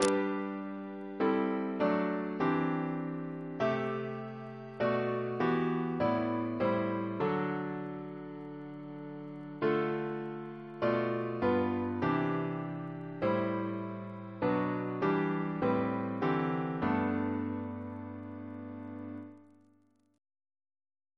Double chant in G Composer